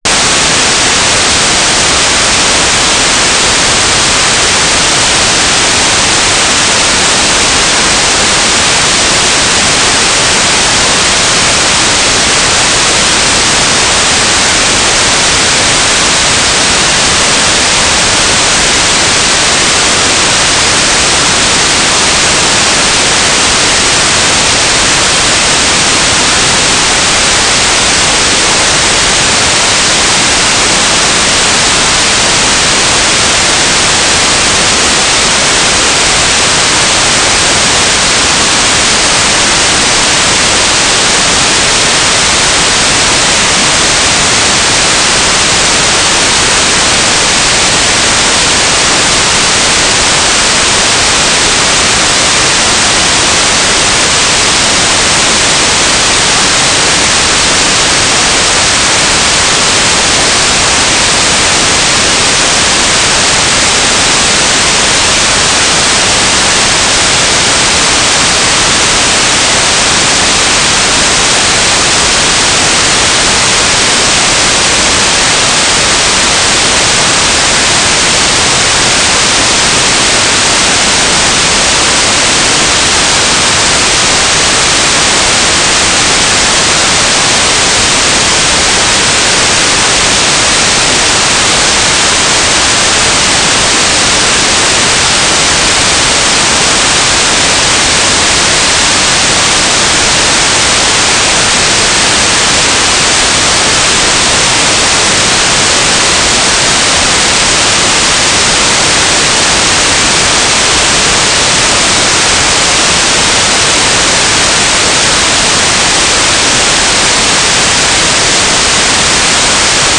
"transmitter_description": "Mode U - GMSK9k6  - AX25 G3RUH - TLM",
"transmitter_mode": "GMSK",